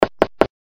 без слов
короткие
стук
Звук появления юзера в чате ICQ